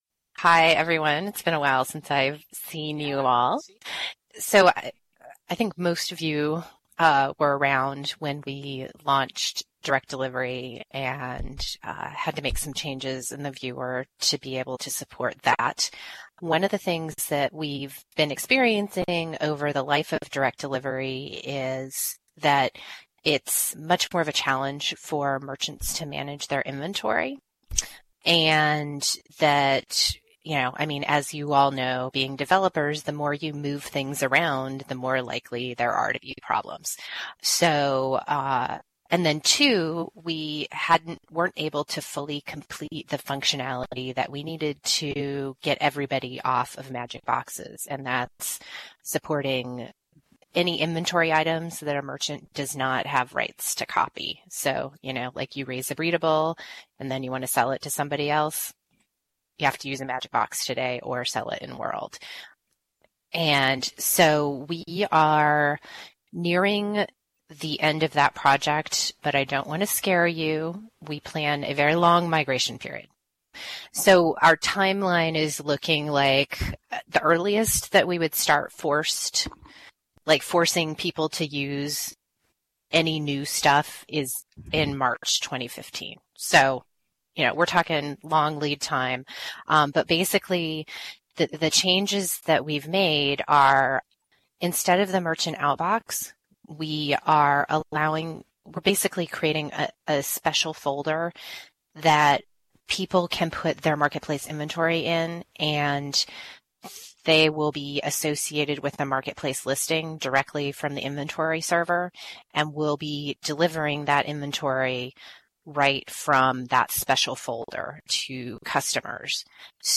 The new functionality is discussed in detail in the October 10th meeting video.